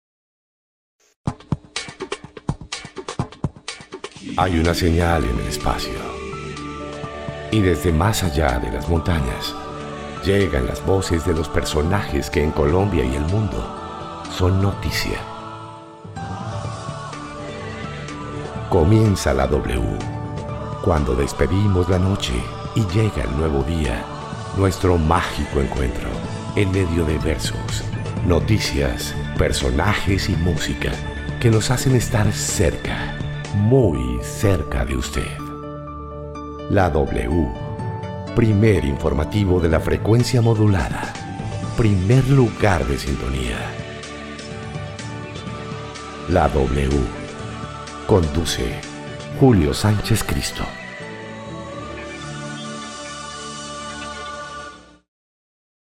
I can perform corporate, serious, deep and friendly voices
I am a professional broadcaster, I speak neutral Spanish, I am from Colombia.
kolumbianisch
Sprechprobe: Werbung (Muttersprache):